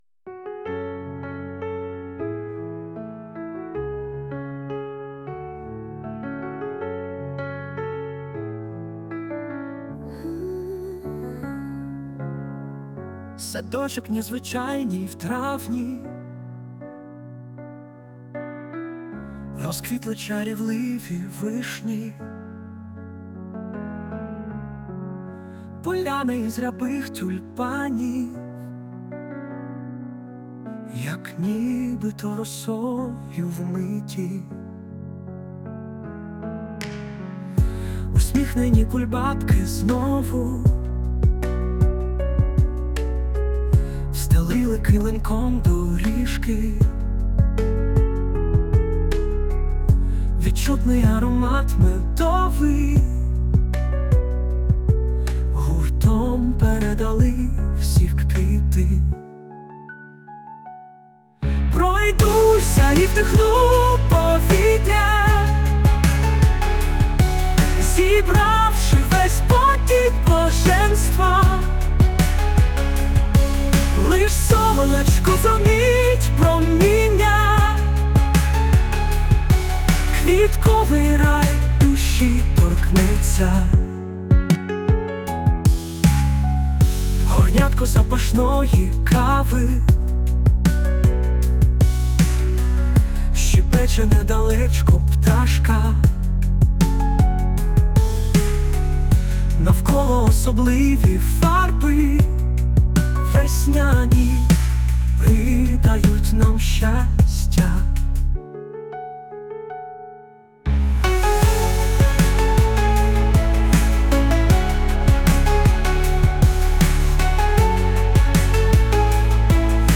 Текст - автора, мелодія і виконання - ШІ
СТИЛЬОВІ ЖАНРИ: Ліричний